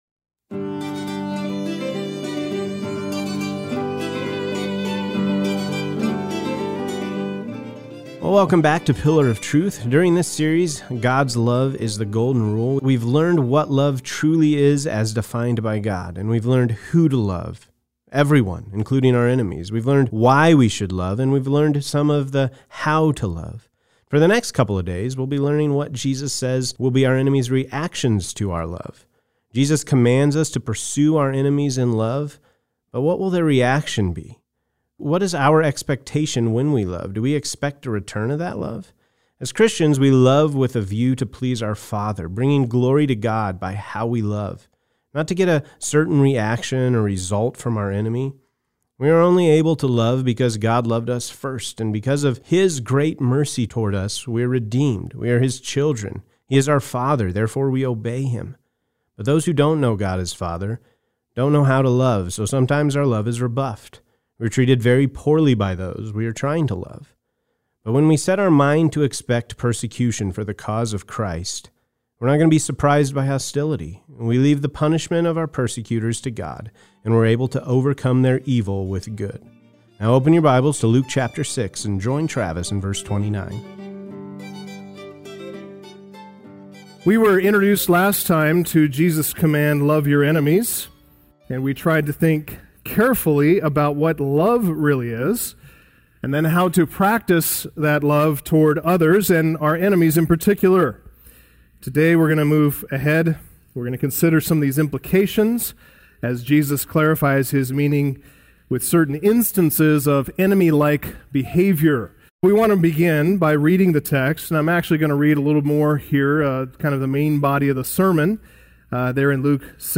Message Transcript